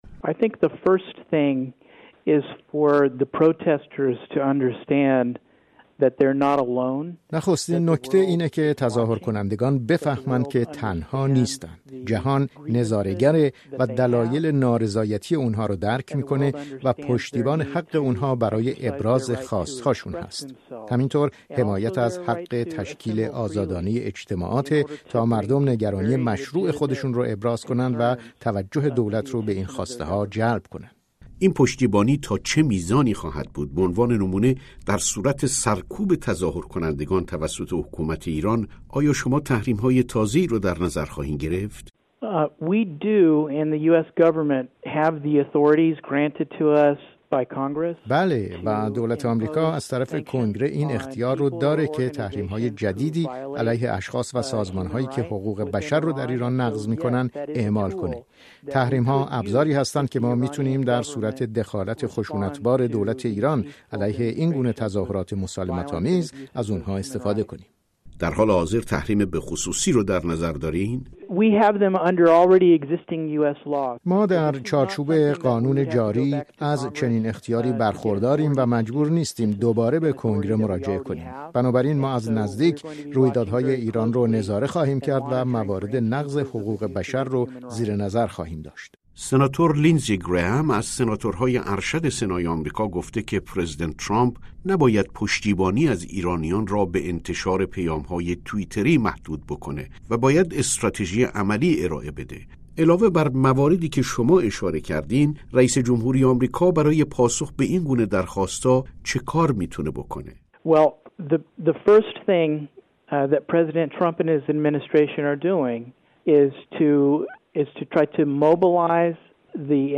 گفتگوی اختصاصی رادیو فردا با جوئل ریبرن، مدیر ارشد شورای امنیت ملی کاخ سفید